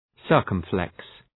Προφορά
{‘sɜ:rkəm,fleks}